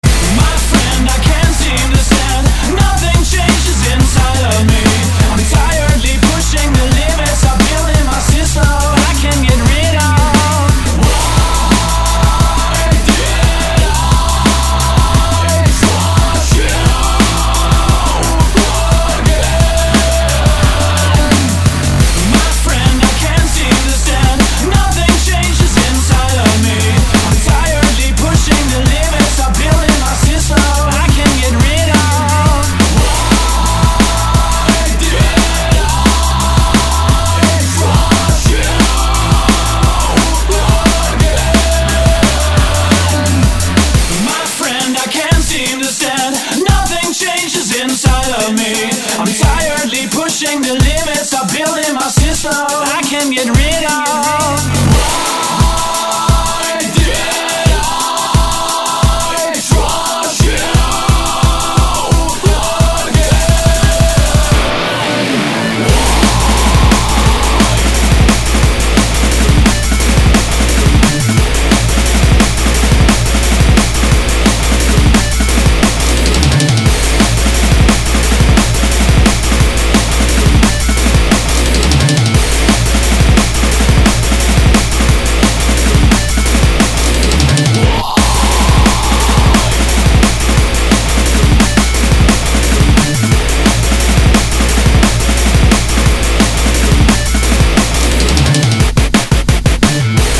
• Качество: 160, Stereo
зажигательные
Electro Metal